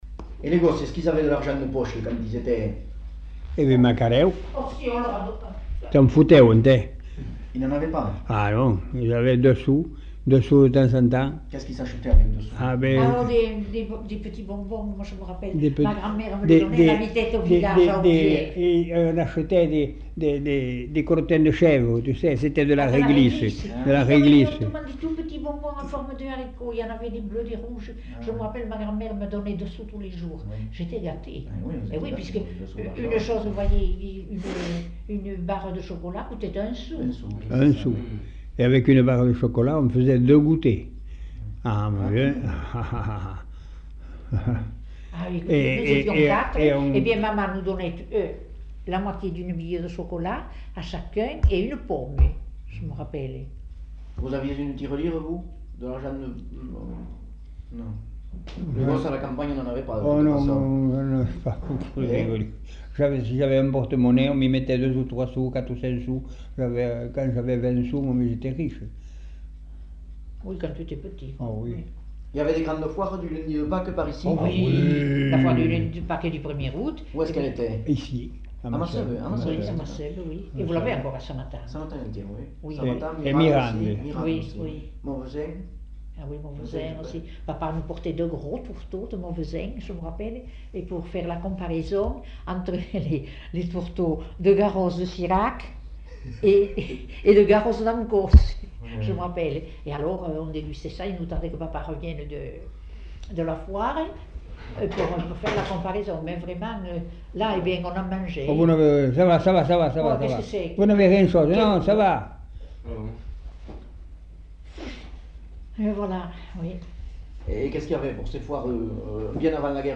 Lieu : Masseube
Genre : récit de vie